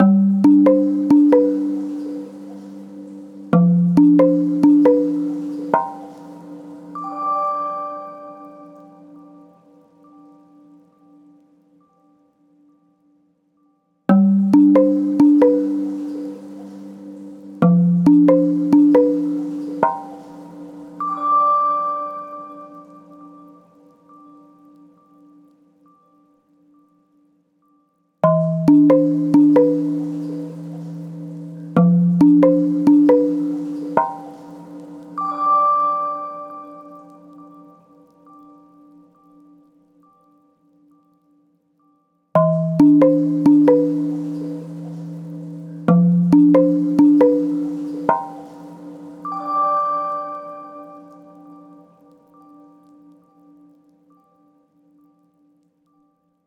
オープンワールド向けにメロディーを最小限に抑えました
南国や温泉風のなんだかちょっぴり温かい気持ちになれる曲です